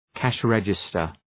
Leave a reply cash register Dëgjoni shqiptimin https